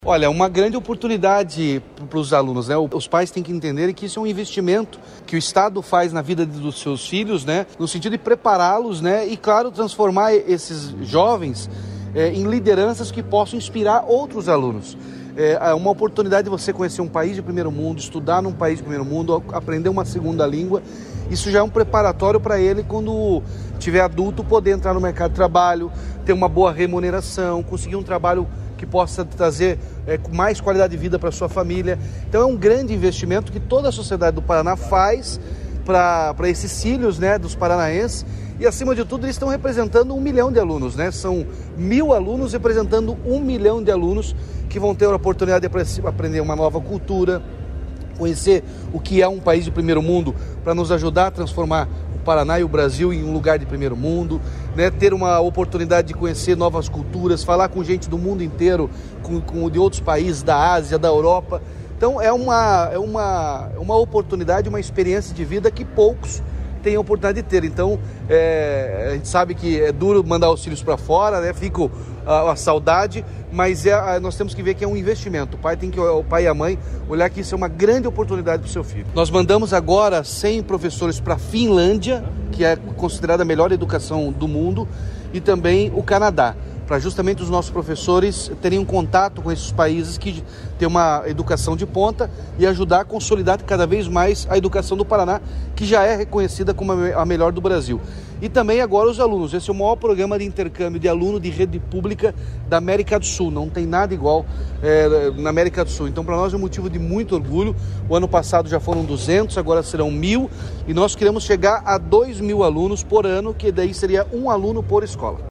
Sonora do governador Ratinho Junior sobre o início das preparações para o Ganhando o Mundo